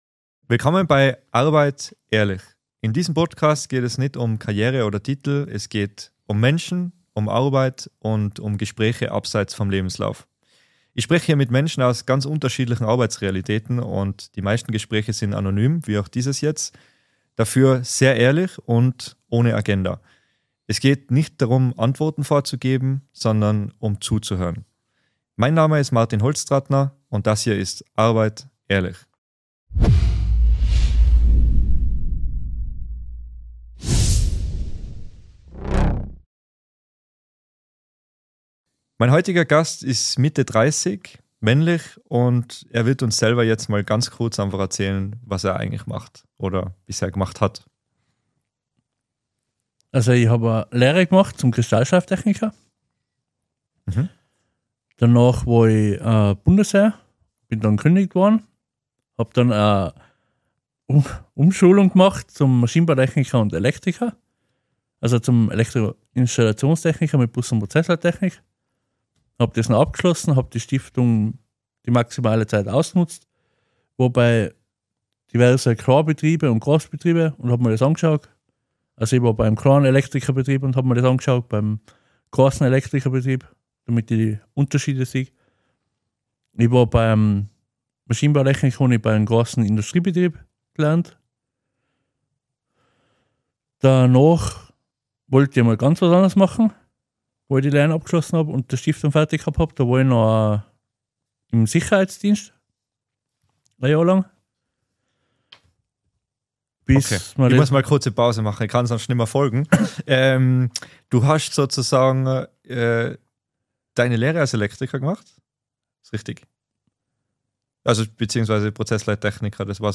Beschreibung vor 1 Monat In dieser Folge von Arbeit. Ehrlich. ist unser Gast ein Lagermitarbeiter aus der Industrie, der mehrere Lehrberufe abgeschlossen hat. Er spricht mit uns über - seine berufliche Laufbahn - Probleme in der Industrie - verschiedene Arten von Chefs und wie er die Probleme in der Praxis gelöst hätte, wenn er selbst die Führungskraft gewesen wäre.